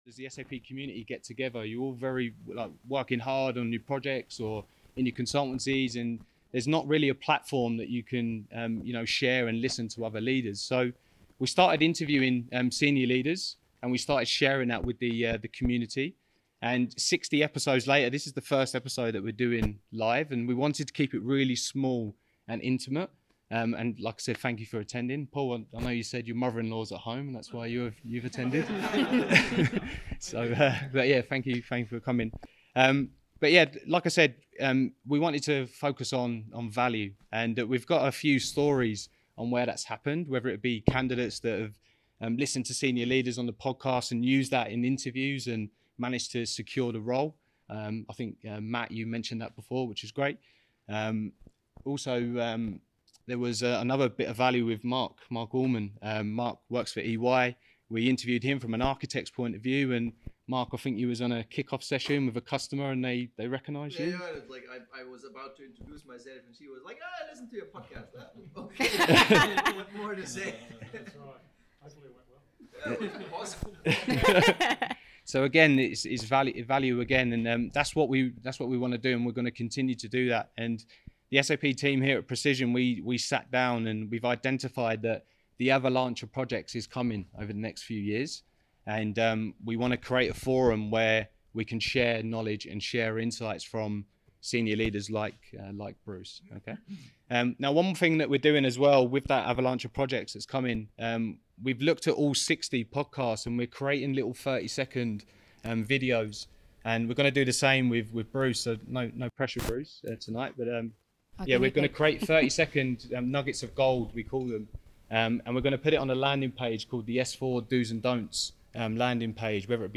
Hosted in front of an intimate audience